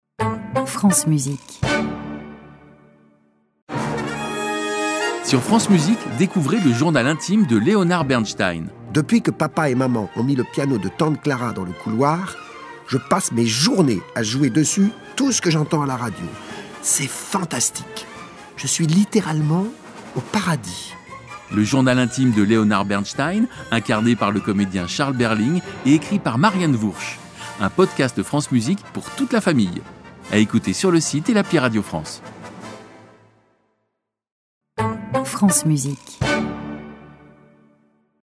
incarné par des comédiens